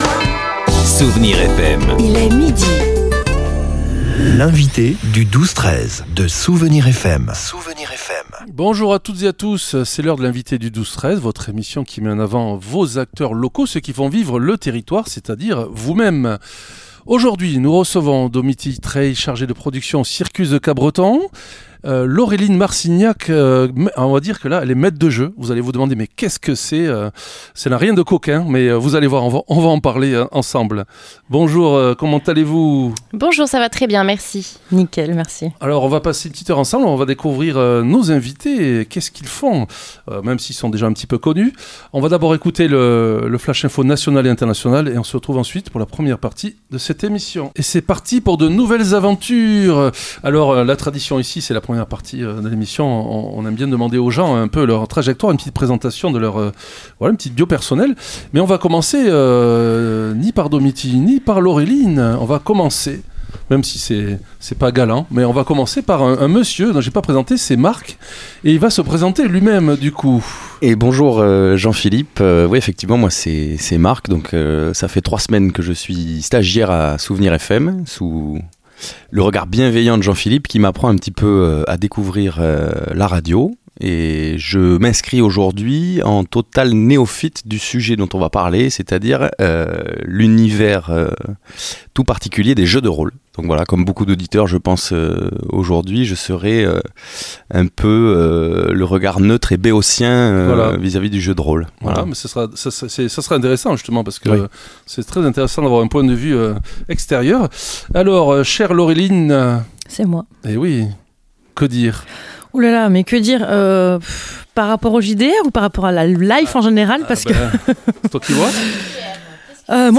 L'entretien a révélé les coulisses du rôle de Maître de Jeu (MJ), véritable créateur d'univers qui guide les joueurs vers une cohésion d'équipe et une narration partagée, que ce soit dans l'héroïque-fantaisie ou l'horreur cosmique de Lovecraft.